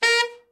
TENOR SN  27.wav